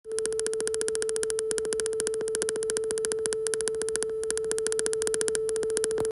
Continuous clicking, less frequent